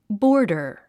発音
bɔ’ːrr　ボォダァ